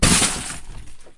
玻璃 " 玻璃破碎小
描述：打破玻璃瓶
标签： 裂缝 玻璃 断裂粉碎 粉碎 紧缩
声道立体声